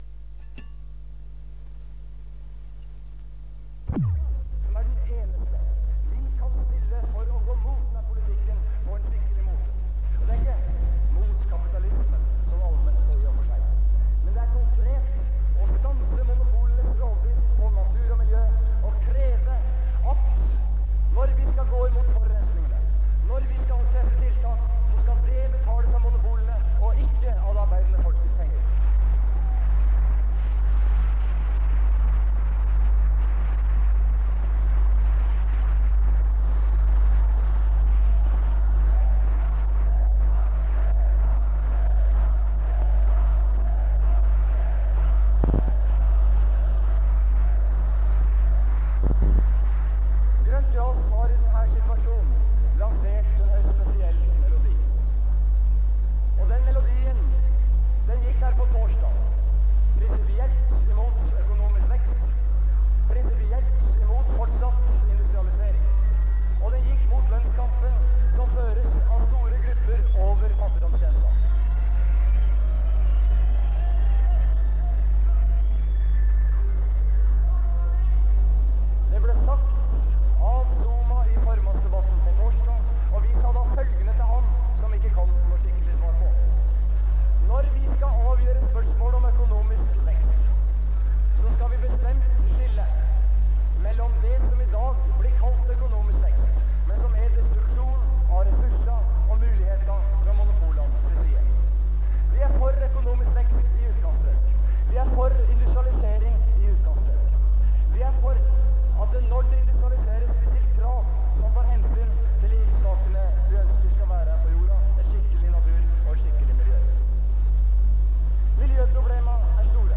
Generalforsamling
Dårlig lyd